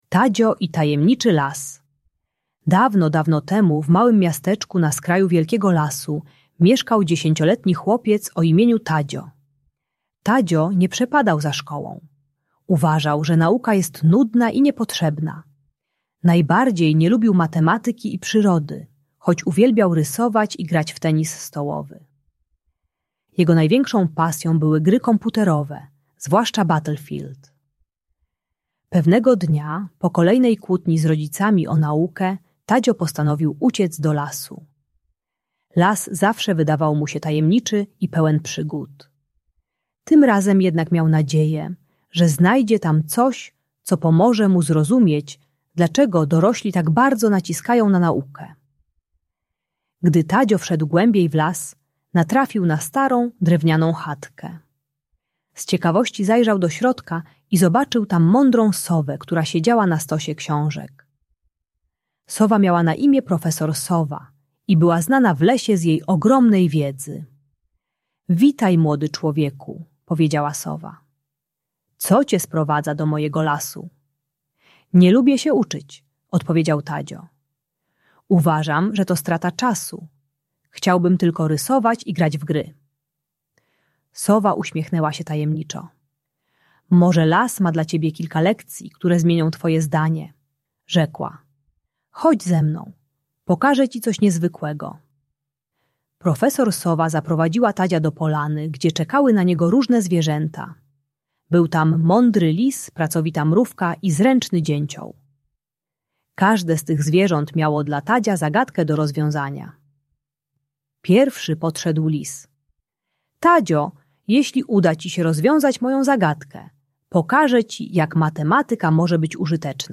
Audiobajka uczy techniki odkrywania praktycznego zastosowania wiedzy szkolnej poprzez ciekawość i łączenie nauki z przygodą.